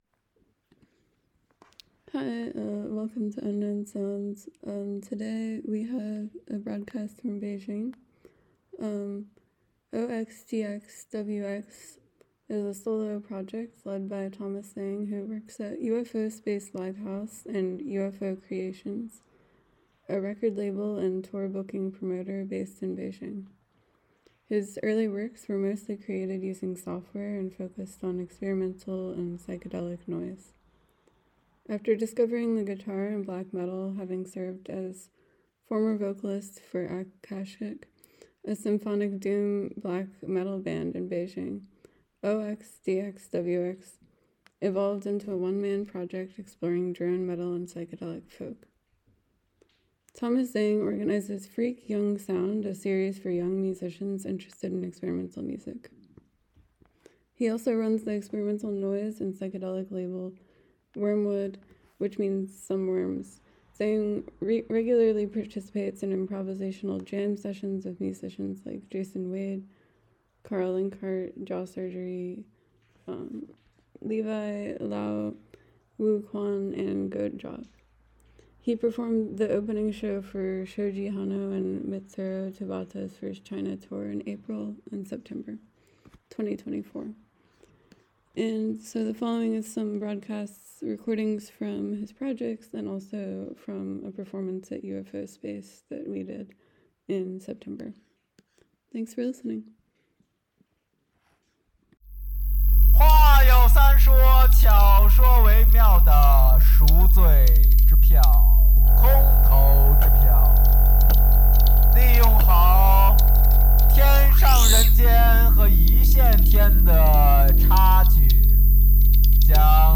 experimental and psychedelic noise made from software
drone metal/psychedelic folk one-man band